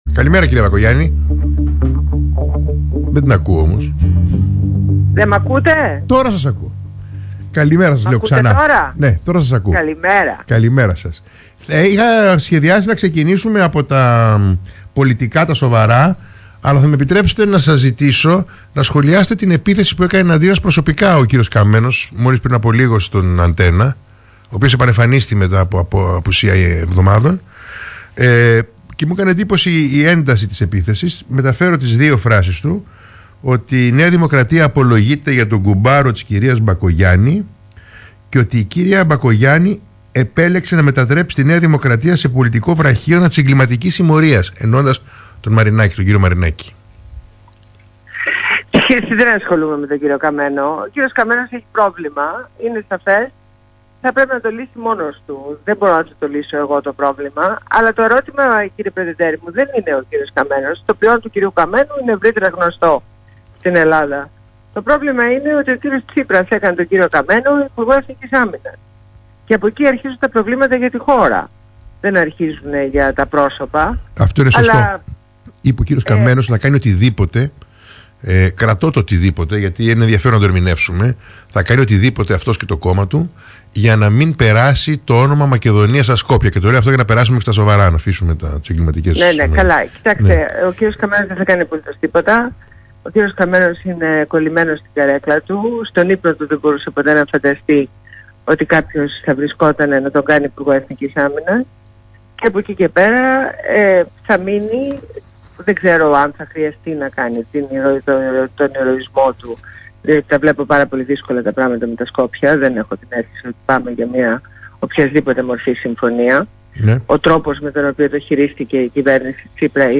Συνέντευξη στο ραδιόφωνο Πρώτο Θέμα